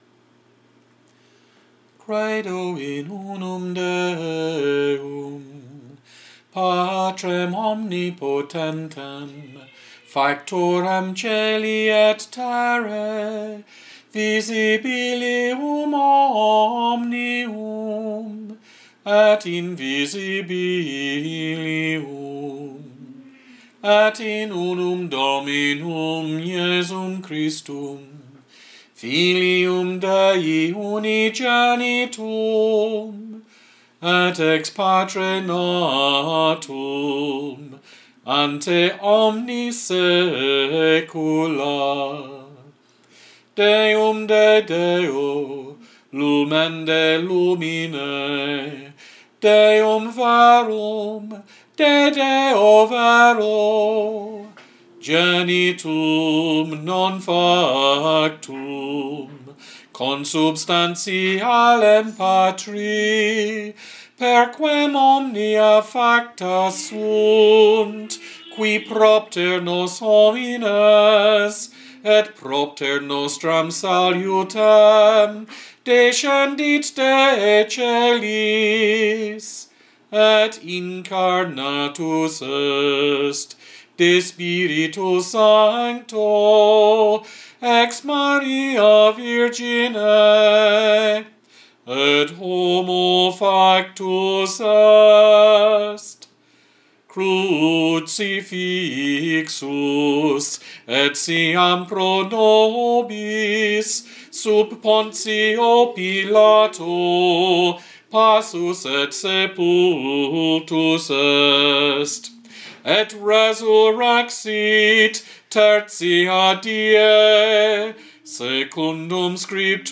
Creed nicene creed latin plainchant male voice a capella